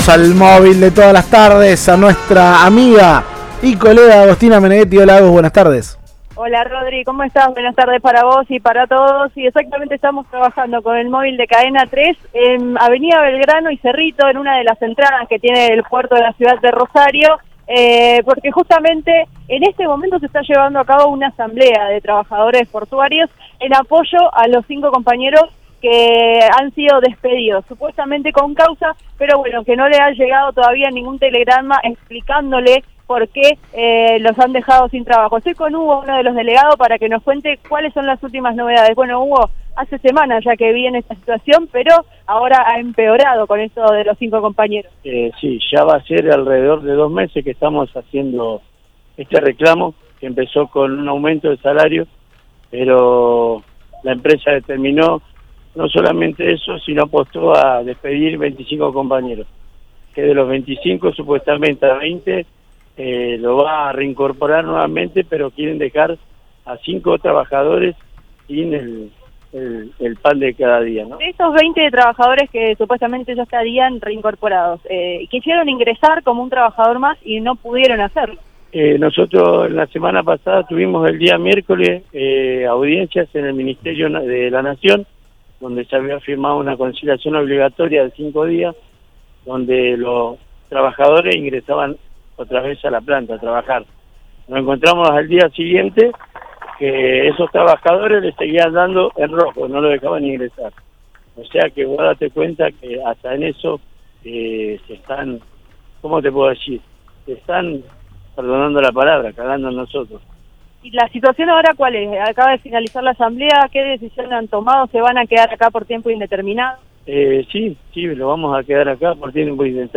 Este jueves, algunos se encadenaron al ingreso y hablaron con Cadena 3 Rosario.